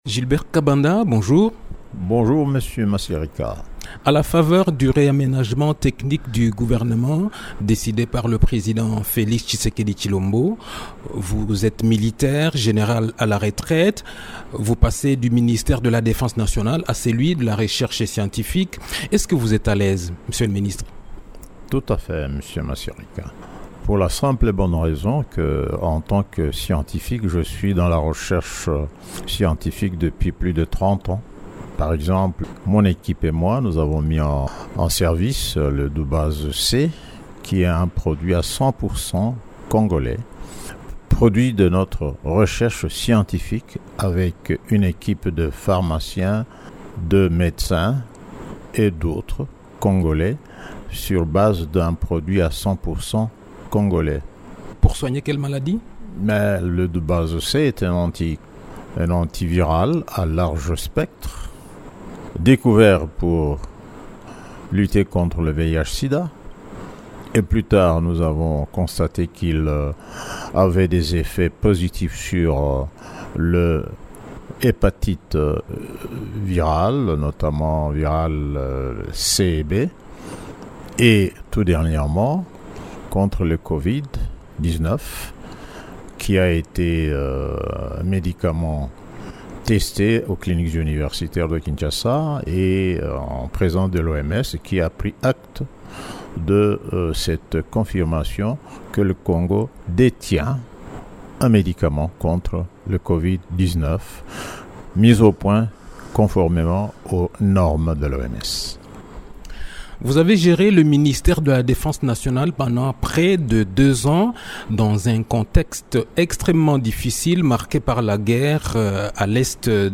Il l’a annoncé au cours d’une interview accordée à Radio Okapi.